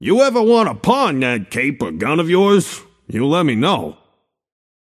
Shopkeeper voice line - You ever wanna pawn that cape or gun of yours, you let me know.